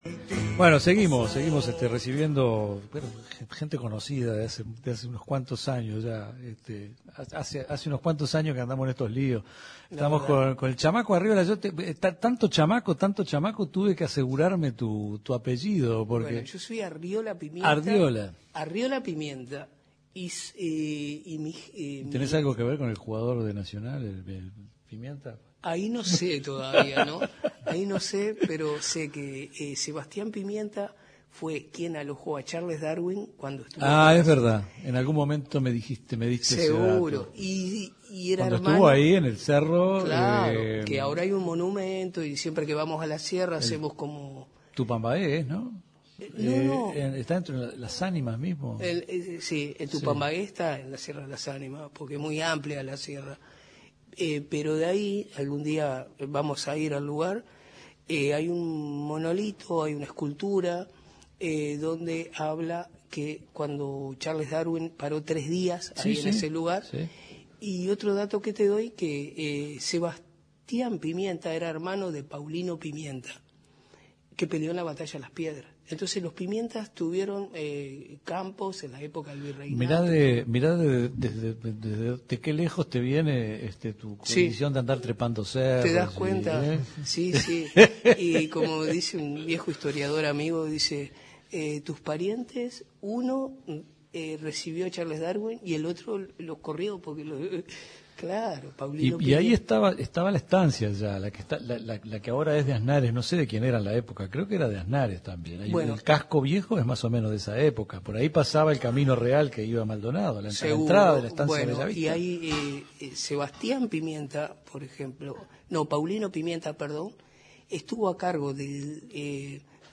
Invitados por Aprotur El Tungue lé llega a Piriápolis para realizar un programa especial